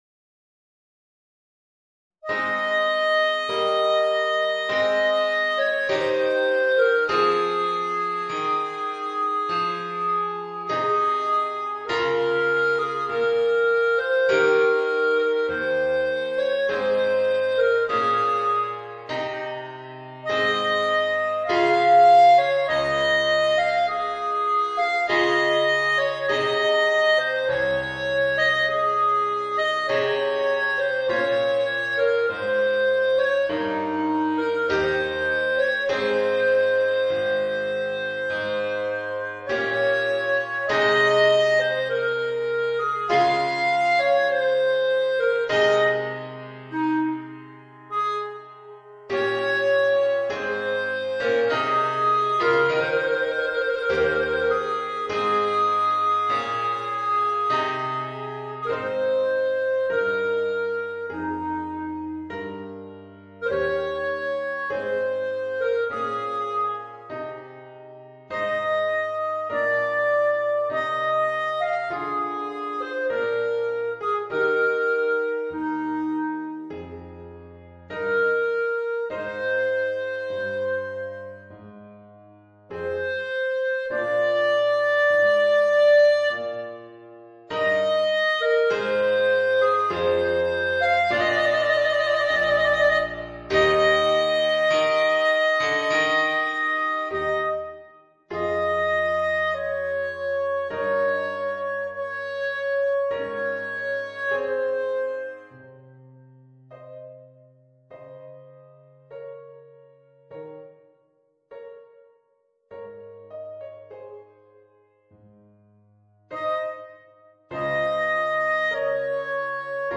Répertoire pour Clarinette